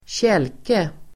Ladda ner uttalet
Uttal: [²tj'el:ke]